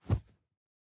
Звуки ваты
Звук падения крупного куска ваты на диван